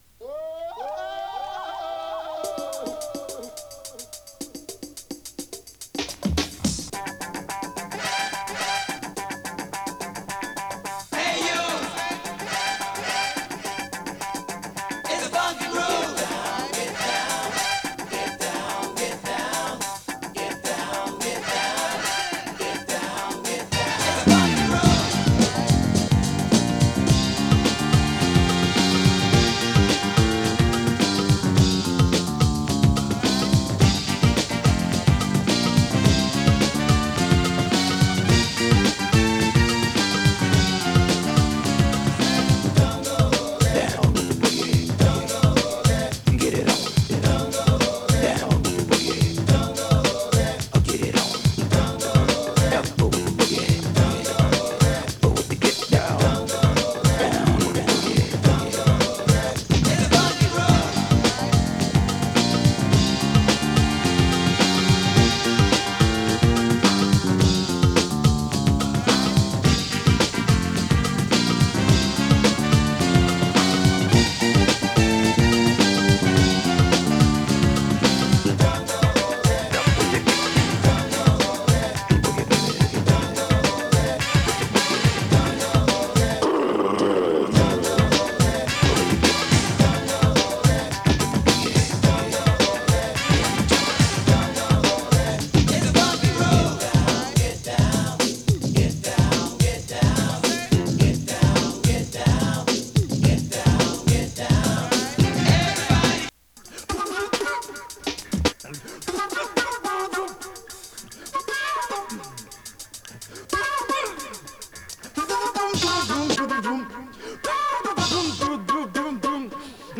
モダンソウル